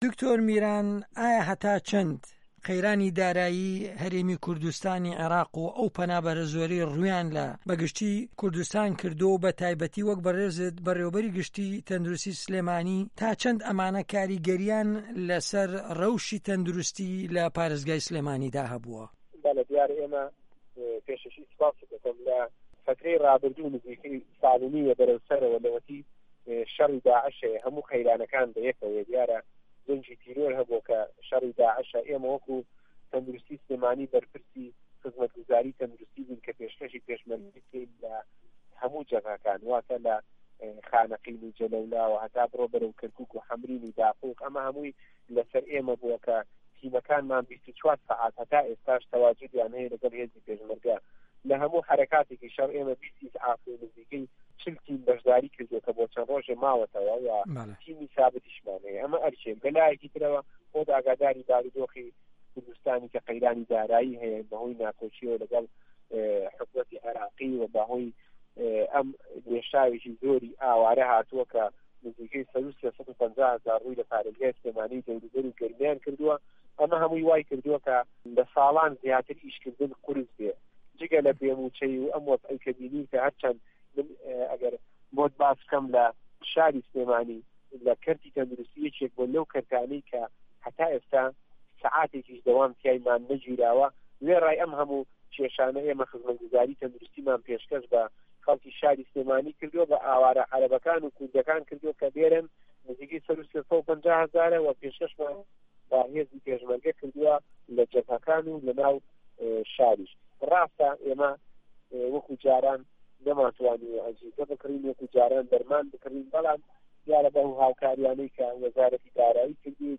وتوێژ لەگەڵ دکتۆر میران مەحەمەد